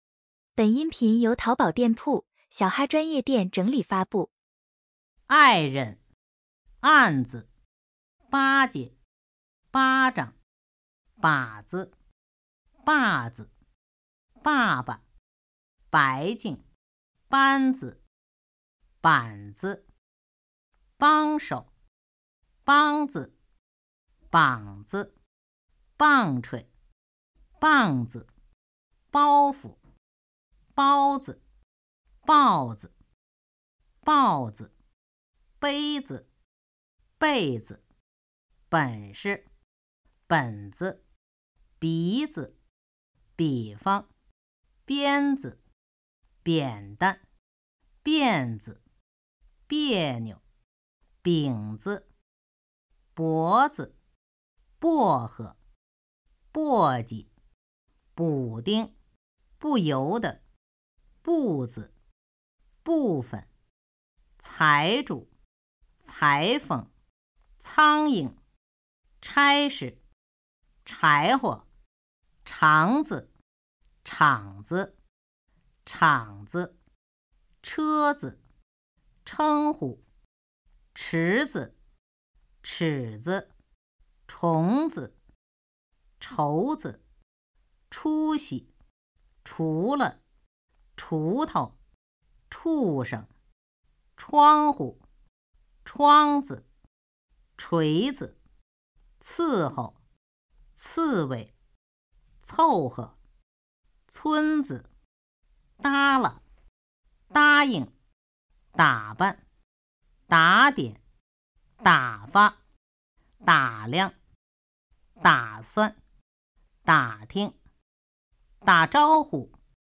轻声1到100.mp3